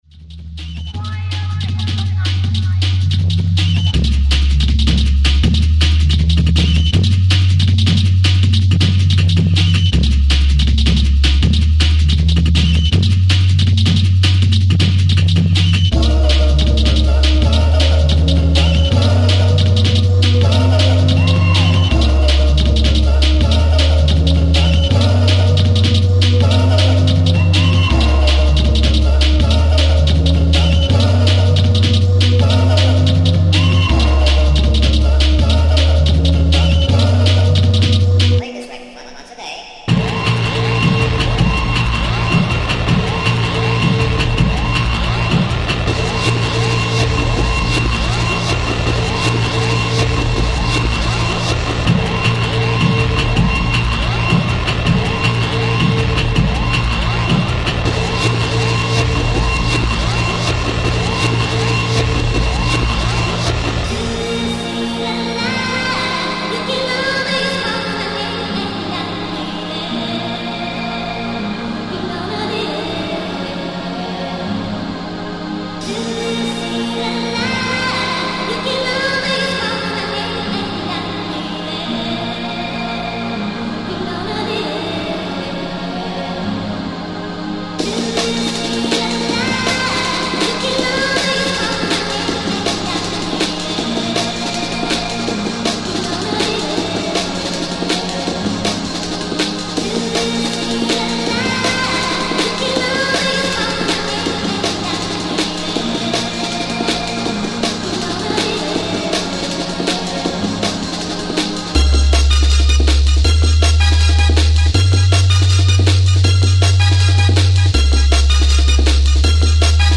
UK Hardcore